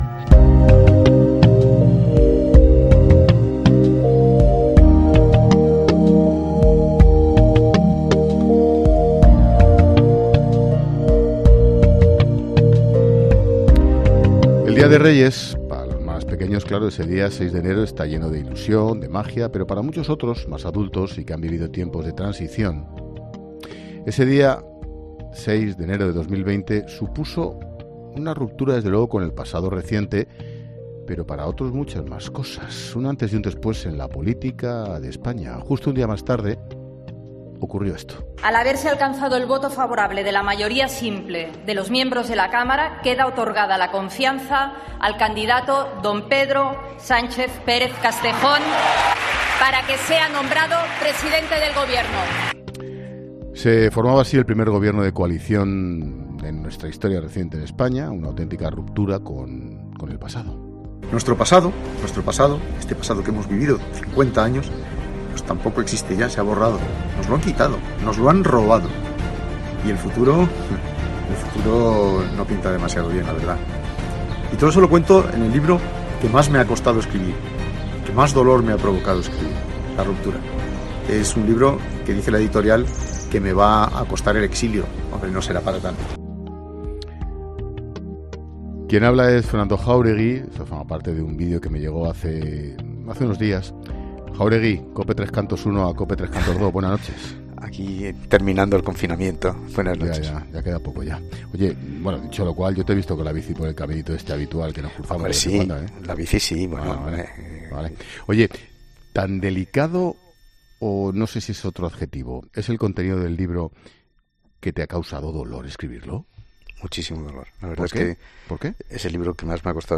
En esta conversación entre Ángel Expósito y Fernando Jáuregui, han querido repasar parte de estos 40 años de la política en España Repasar por protagonistas.